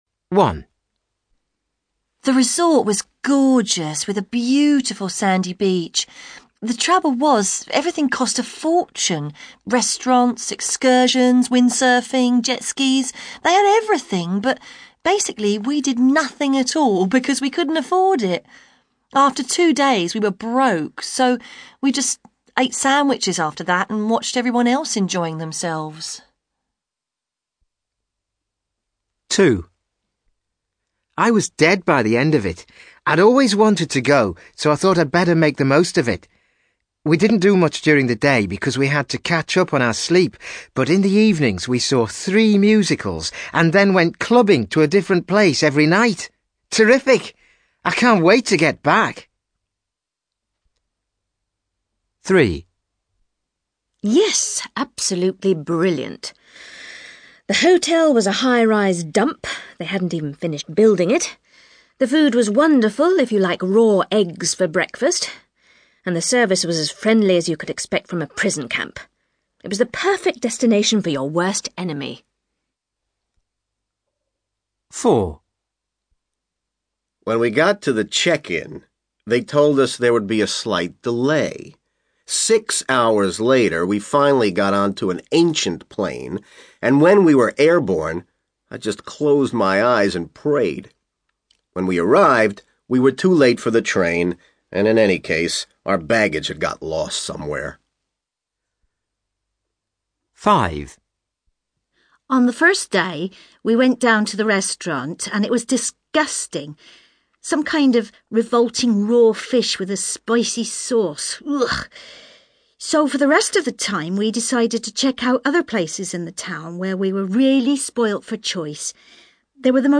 To do the second part , click on Go on with the Listening LISTEN TO SIX PEOPLE TALKING ABOUT THEIR HOLIDAYS AND MATCH THE TOPIC WITH EACH SPEAKER Click here to listen Check Check OK Index Go on with the listening